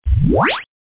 1 channel
wooeep.mp3